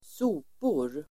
Uttal: [²s'o:por]